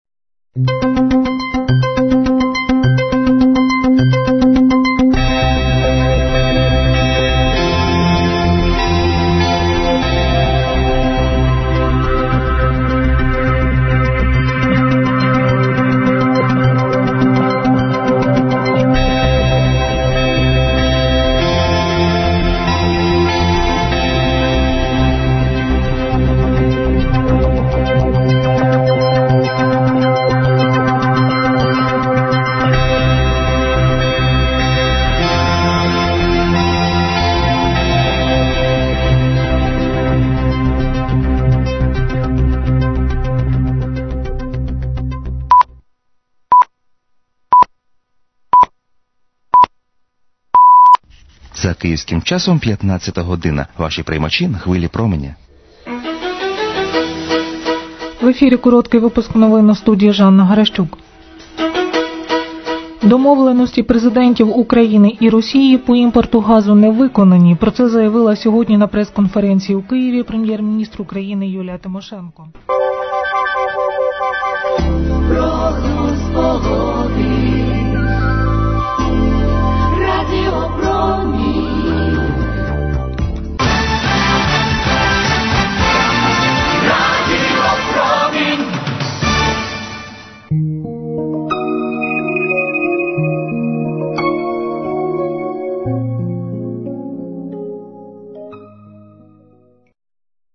позивні 2001 р.